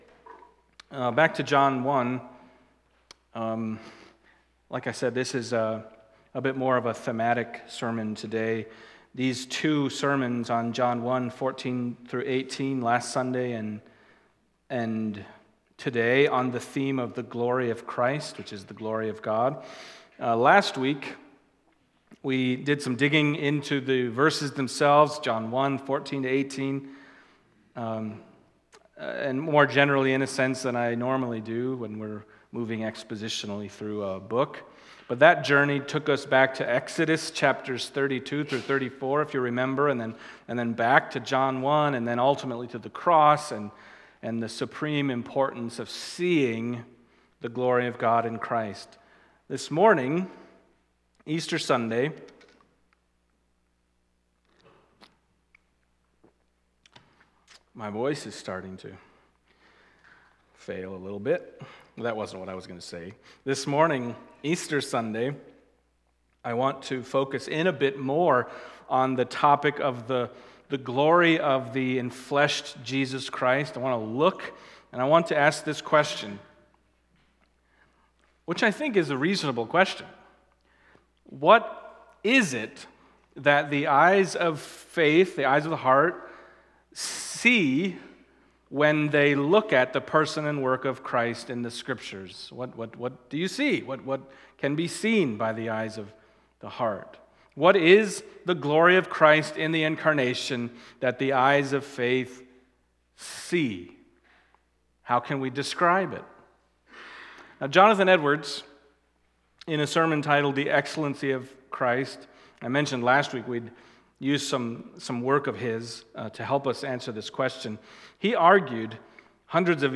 Other Passage: John 1:14-18 Service Type: Sunday Morning John 1:14-18 « We Have Seen His Glory…